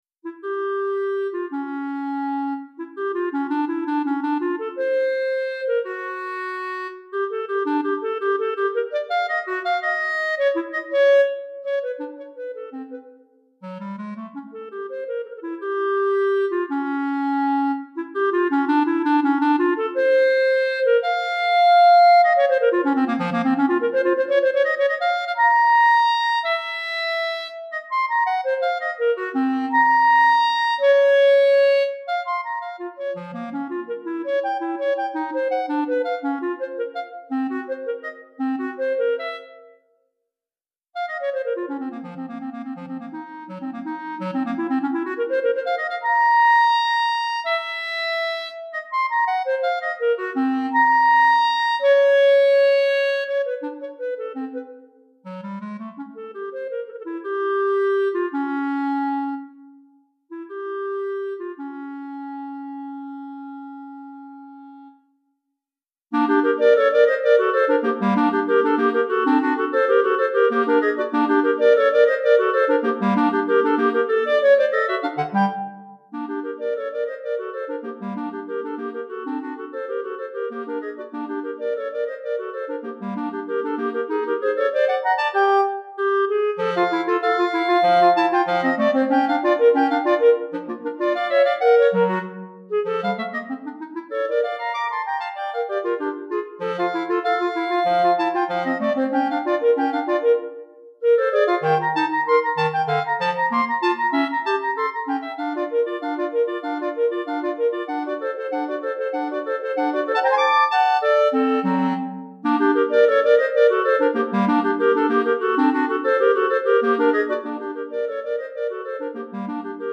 2 Clarinettes en Sib et Clarinette Basse